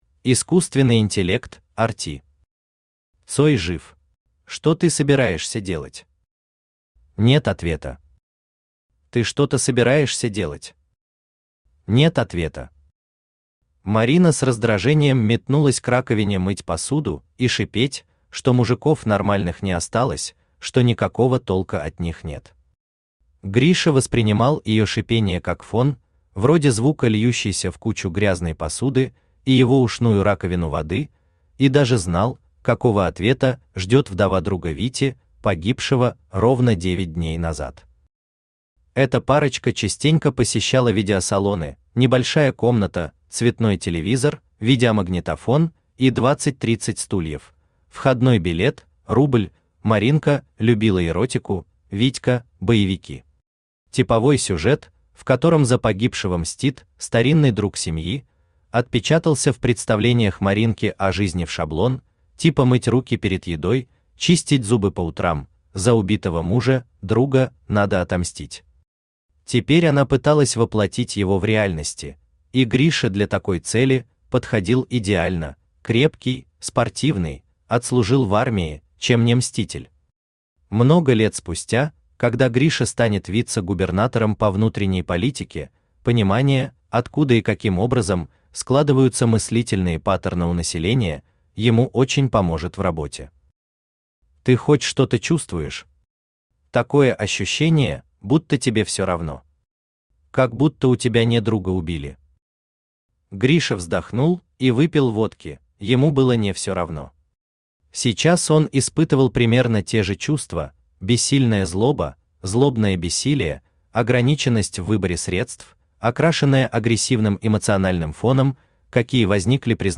Aудиокнига Цой жив Автор Искусственный Интеллект RT Читает аудиокнигу Авточтец ЛитРес.